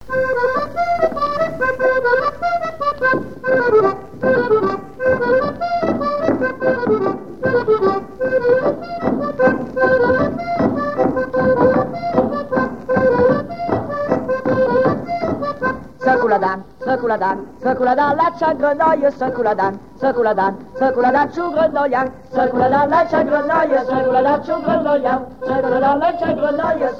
Mémoires et Patrimoines vivants - RaddO est une base de données d'archives iconographiques et sonores.
branle
Chants brefs - A danser
airs de danses issus de groupes folkloriques locaux
Pièce musicale inédite